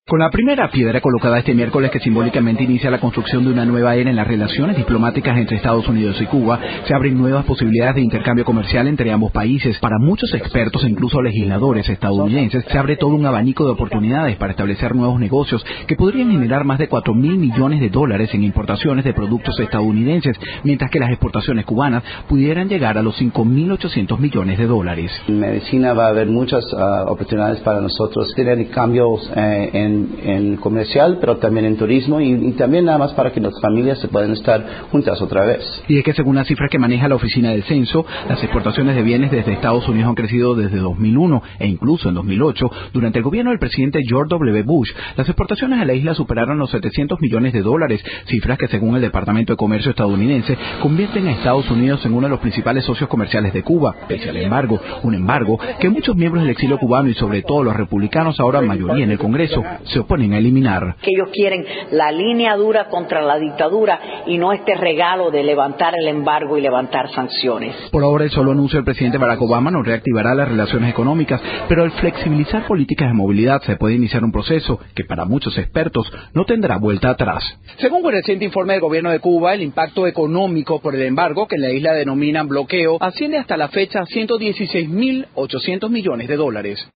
A propósito de las conversaciones entre Cuba y Estados Unidos, aquí las declaraciones de los congresistas estadounidenses Rubén Gallego e Ileana Ros-Lehtinen.